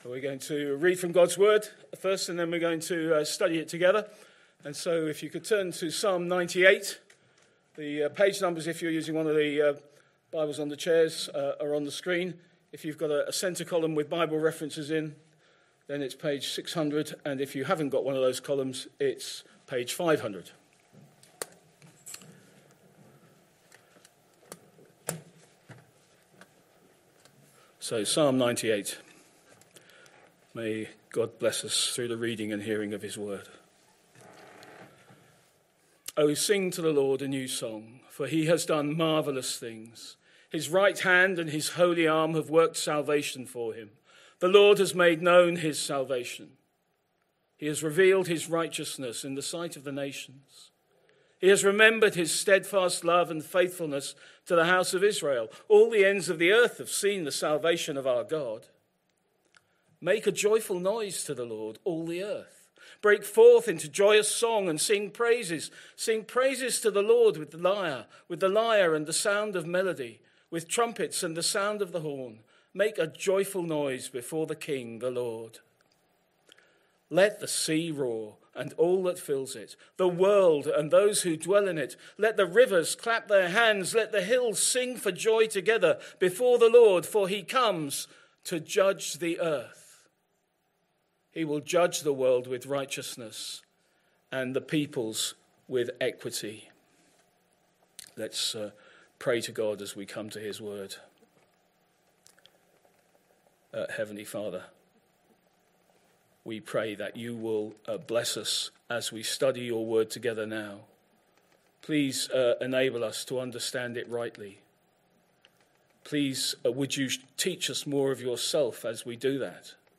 Sunday PM Service Sunday 5th October 2025 Speaker